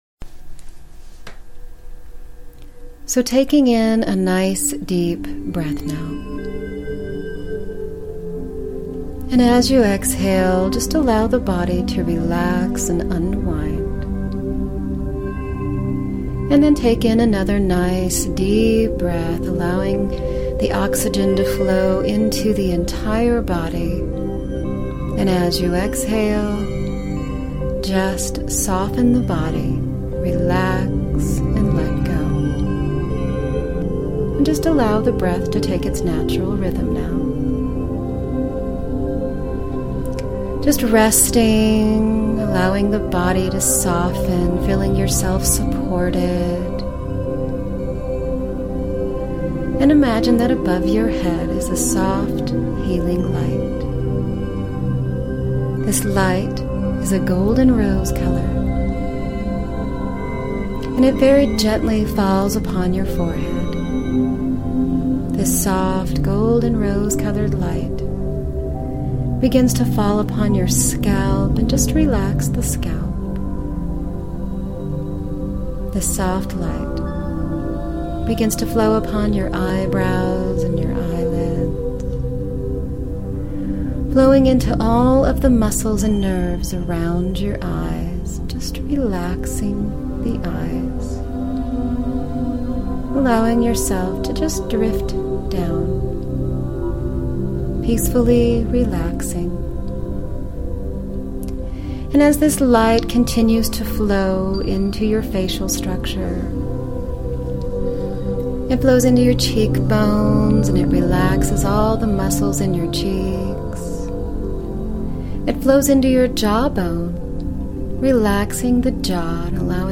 A self-hypnosis medition to deepen into a state of gratitude, confidence, and worthiness.